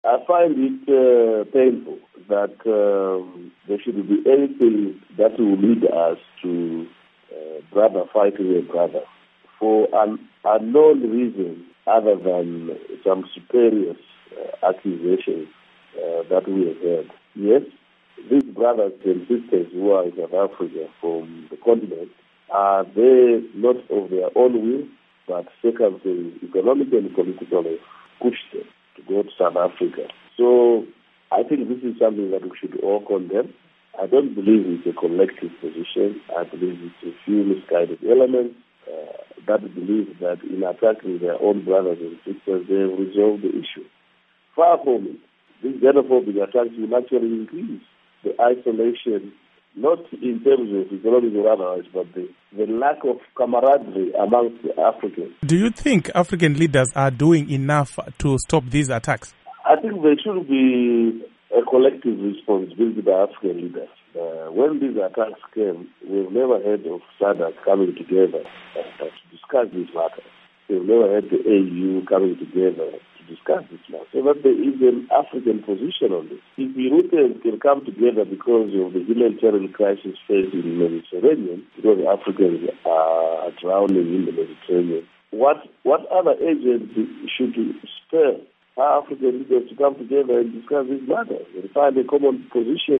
Interview With Morgan Tsvangirai on Xenophobia